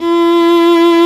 Index of /90_sSampleCDs/Roland - String Master Series/STR_Viola Solo/STR_Vla2 % + dyn